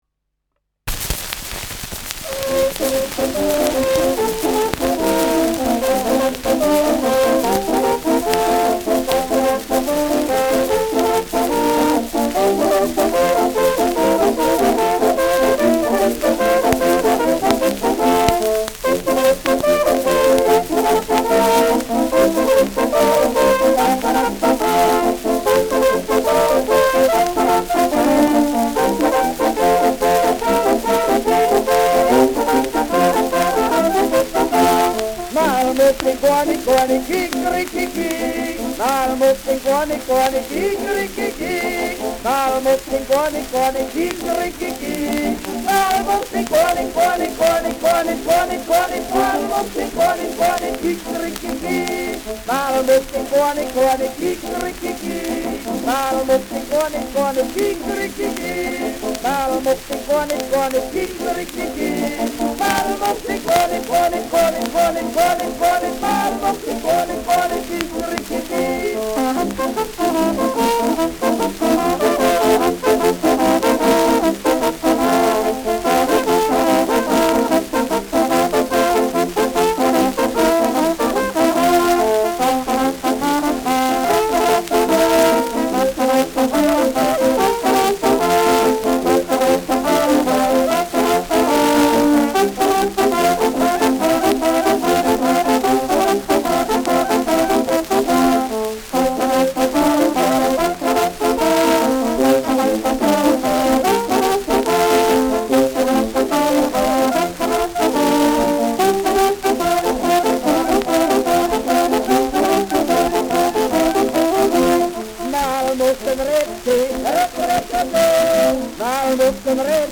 mit Gesang
Schellackplatte